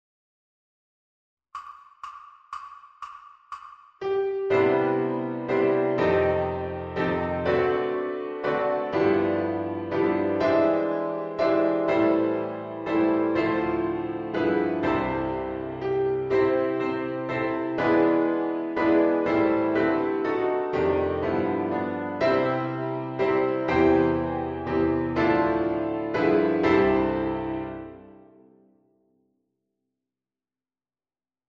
We-Wish-You-a-Merry-Christmas-Piano-Christmas-1.3.0-ViolinSchool.mp3